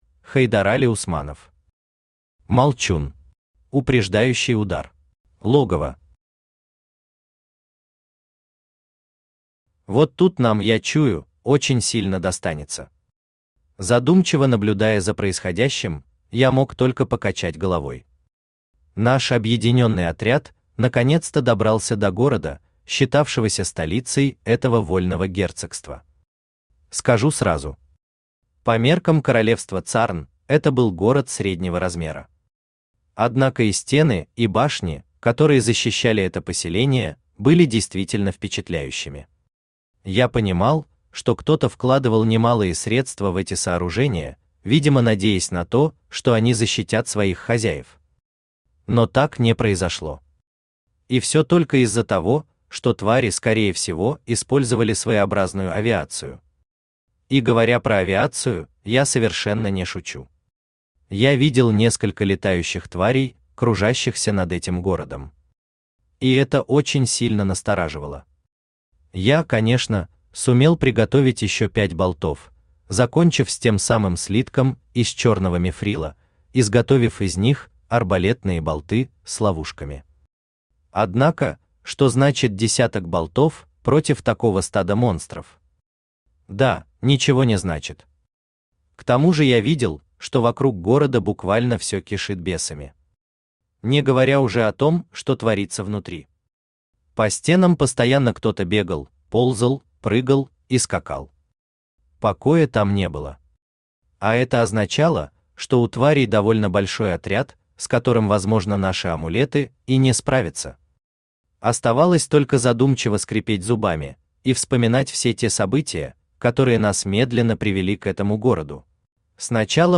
Аудиокнига Молчун. Упреждающий удар | Библиотека аудиокниг
Упреждающий удар Автор Хайдарали Усманов Читает аудиокнигу Авточтец ЛитРес.